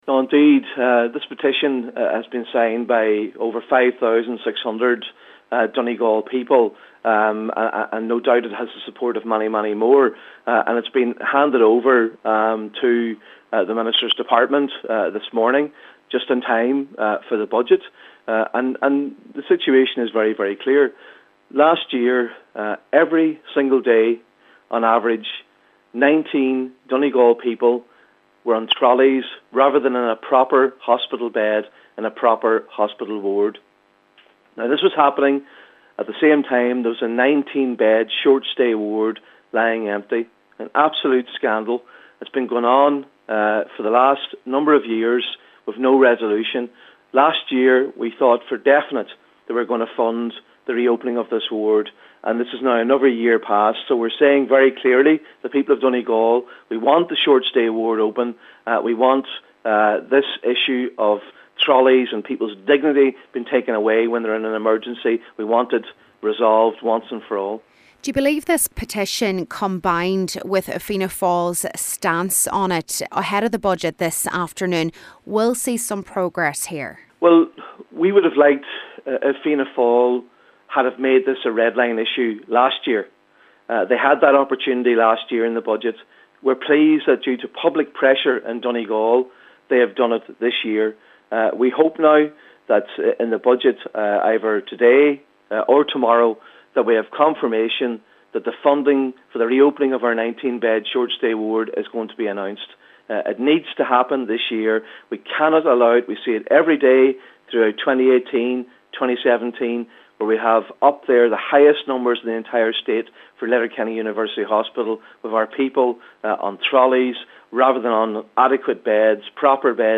Donegal Senator Padraig Mac Lochlainn is hopeful that the petition will put further pressure on the Health Minister: